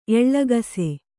♪ eḷḷagase